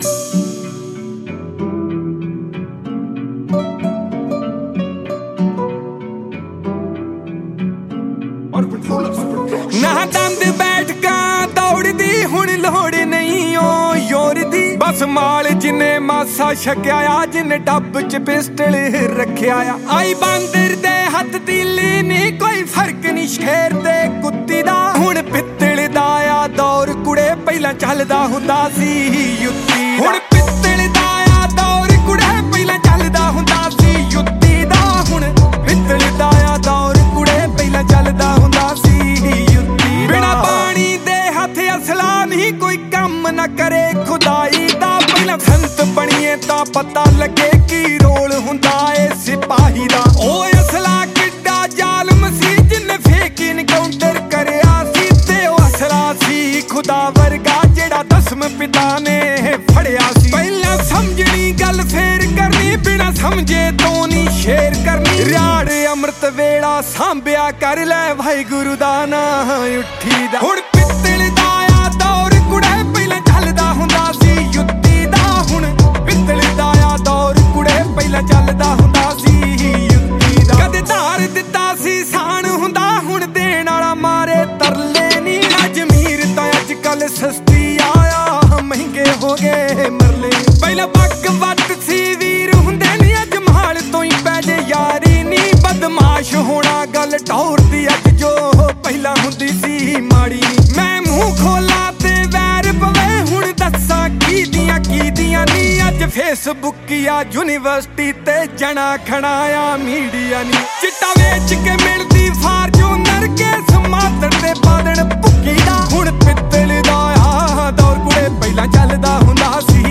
Punjabi Bhangra
Indian Pop